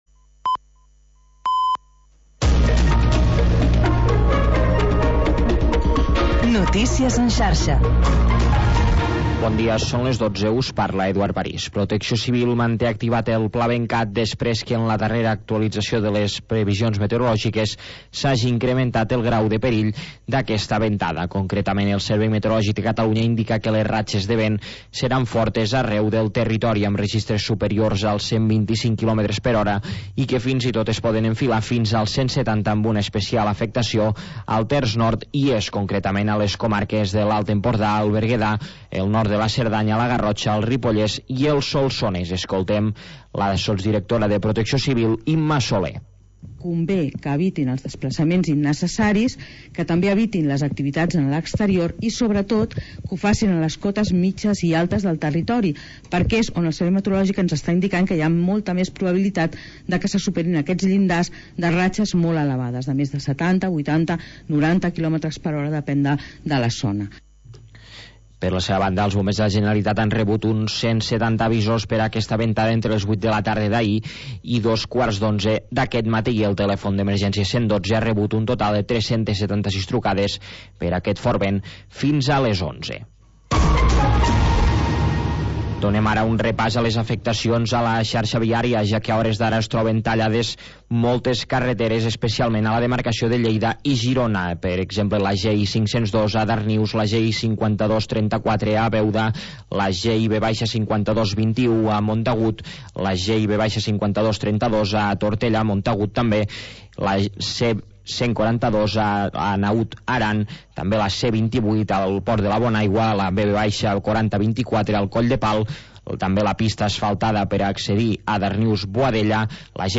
Música nostàlgica dels anys 50, 60 i 70